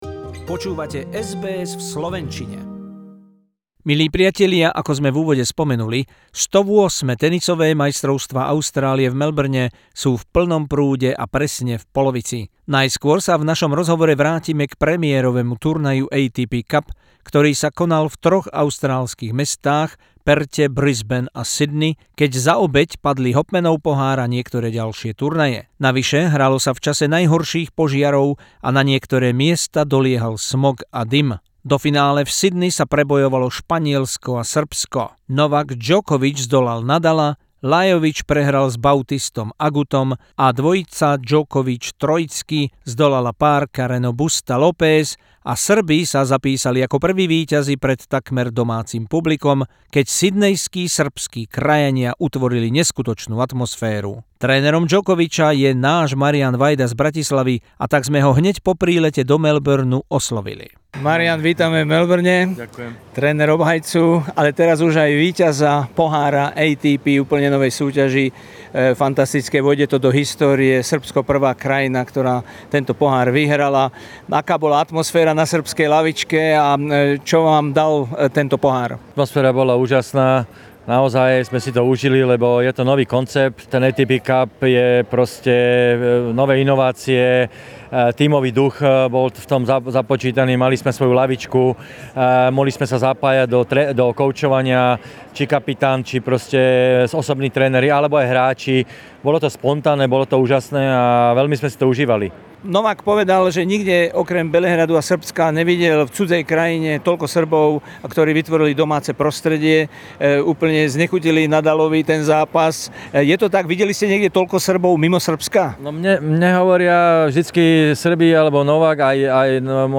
Interview with the world most successful tennis coach Marian Vajda about another huge win of his player Novak Djokovic for Serbia.